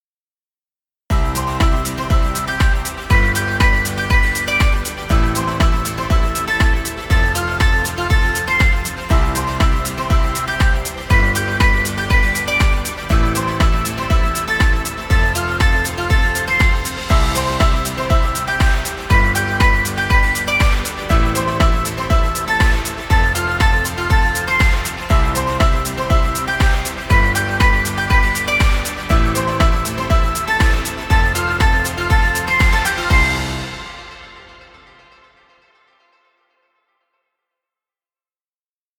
Inspiring motivational music.